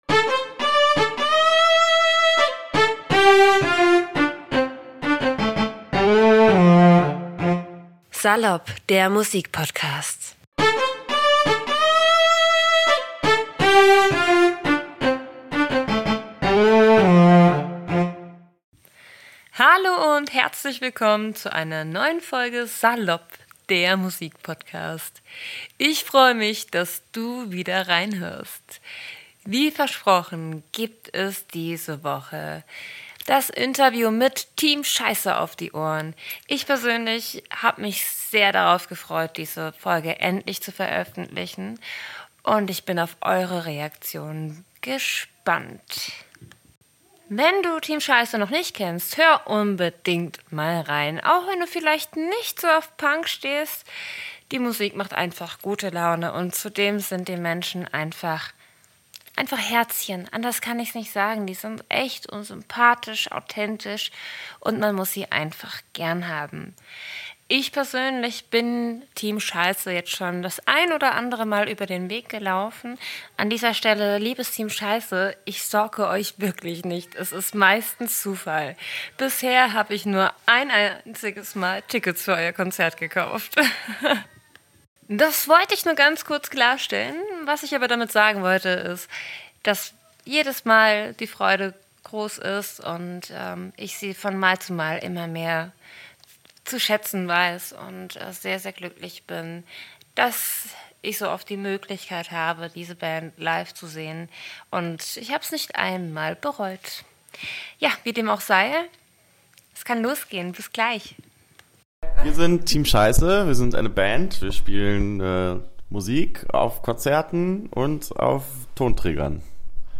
Beschreibung vor 1 Jahr In dieser Episode habe ich die Sympathiegranaten von Team Scheisse im Interview. Die Lieben haben sich vor ihrem Konzert in Frankfurt im Zoom Club extra ein wenig Zeit für mich genommen um mir ein paar Fragen zu beantworten. Ob es hierbei wirklich um Pferde ging, erfahrt ihr in diesem Podcast!